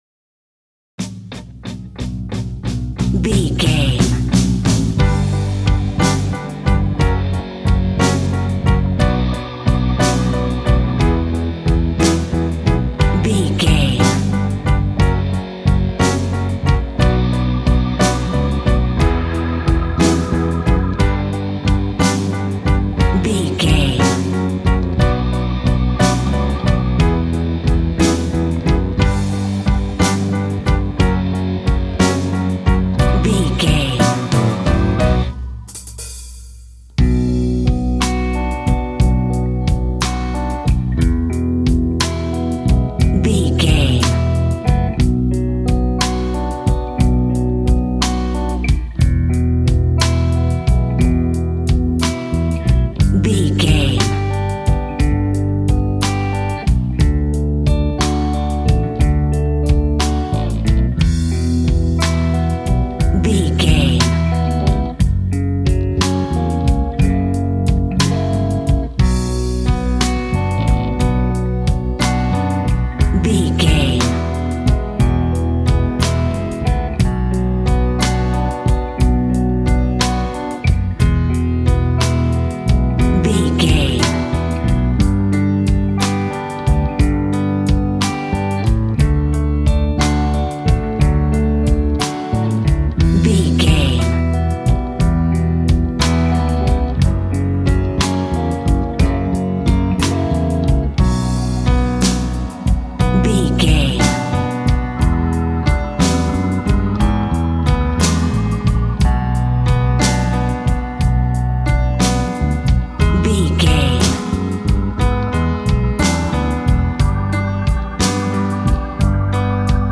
Aeolian/Minor
sad
mournful
bass guitar
electric guitar
electric organ
drums